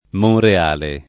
[ monre # le ]